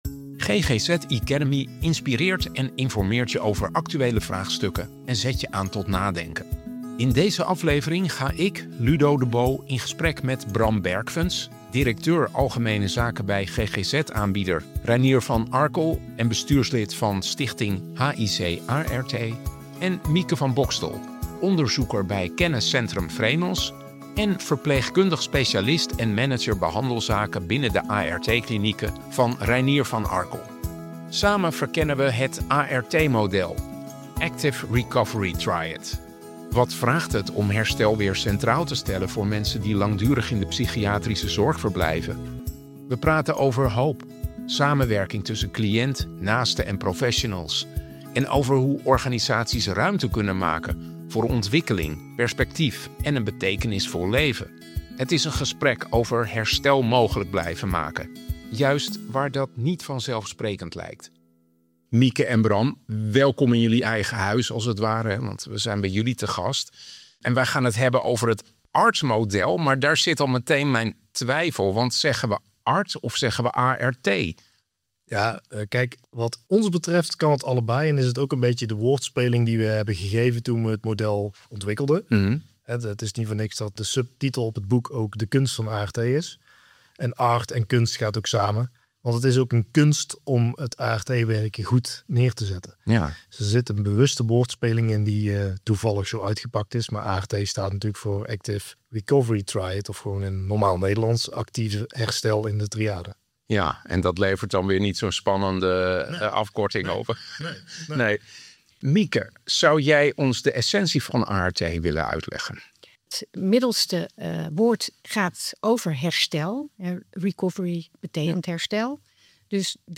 We praten over hoop, de samenwerking tussen cliënt, naasten en professionals en over hoe organisaties ruimte kunnen maken voor ontwikkeling, perspectief en een betekenisvol leven. Het is een gesprek over herstel mogelijk blijven maken, juist waar dat niet vanzelfsprekend lijkt.